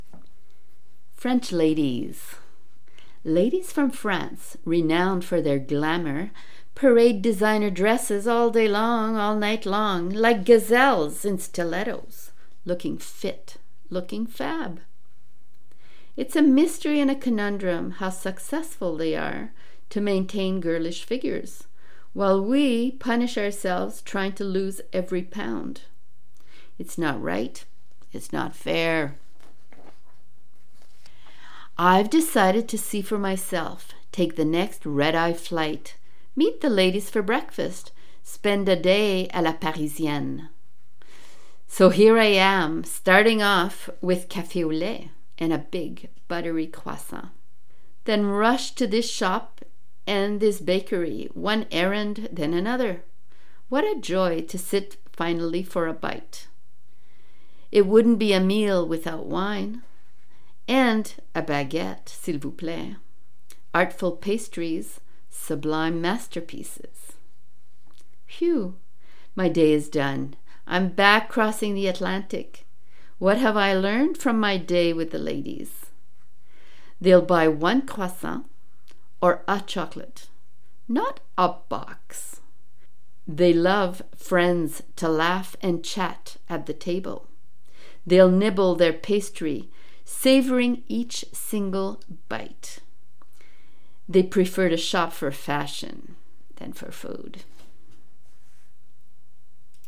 Read on air by invitation  ~  April 28, 2021  'OPEN POETRY WITH WOLF SPIRIT'